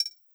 GenericButton8.wav